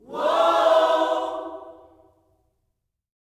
WHOA-OHS10.wav